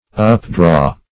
updraw - definition of updraw - synonyms, pronunciation, spelling from Free Dictionary Search Result for " updraw" : The Collaborative International Dictionary of English v.0.48: Updraw \Up*draw"\, v. t. To draw up.